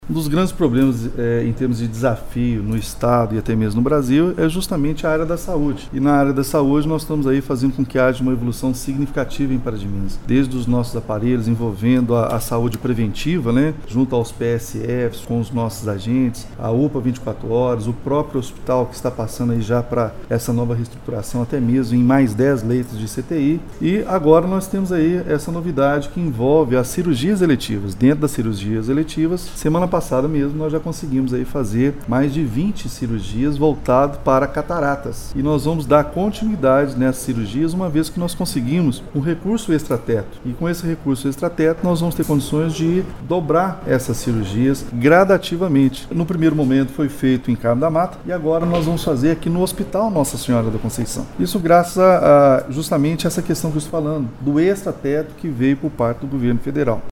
Segundo o prefeito Elias Diniz (PSD) foram realizadas 20 cirurgias de catarata em Carmo da Mata. Informou que o governo federal enviou um recurso extra e esses valores possibilitarão novos procedimentos no Hospital Nossa Senhora da Conceição (HNSC):